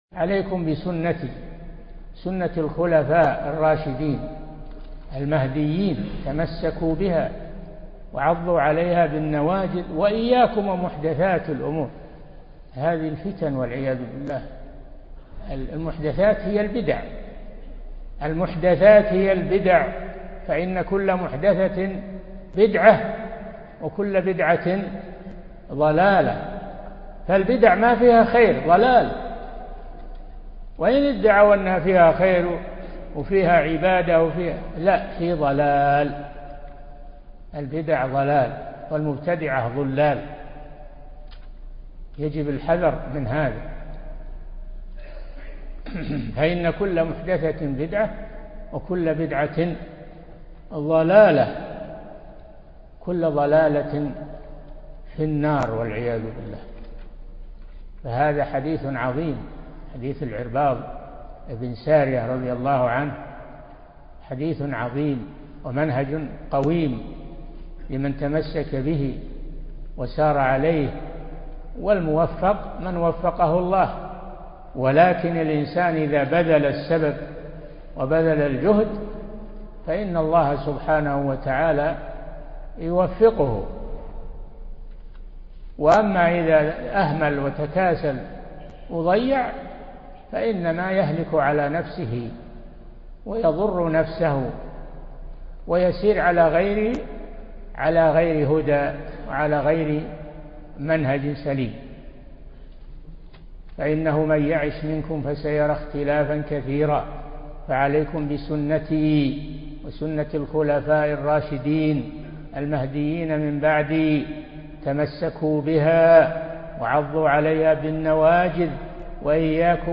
Album: موقع النهج الواضح Length: 6:11 minutes (1.49 MB) Format: MP3 Mono 22kHz 32Kbps (VBR)